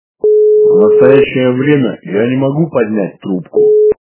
» Звуки » Люди фразы » Сонный мужской голос - На даный момент я не могу поднять трубку
При прослушивании Сонный мужской голос - На даный момент я не могу поднять трубку качество понижено и присутствуют гудки.